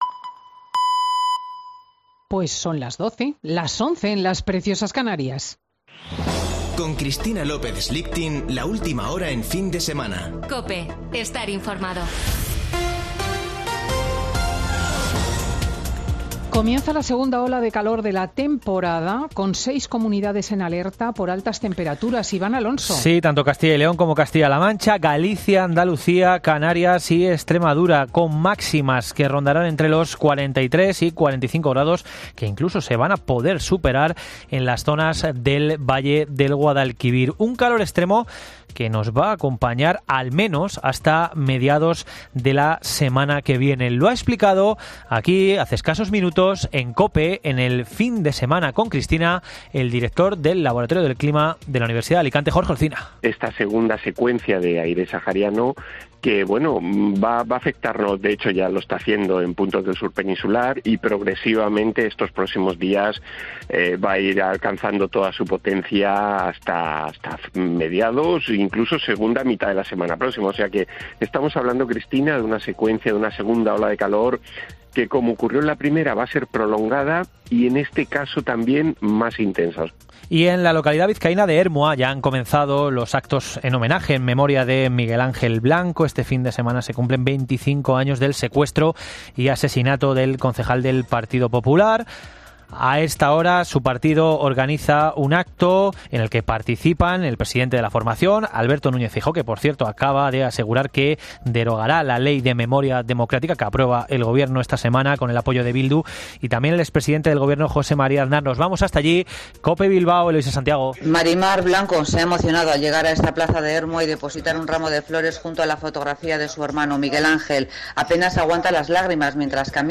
Boletín de noticias de COPE del 9 de julio de 2022 a las 12:00 horas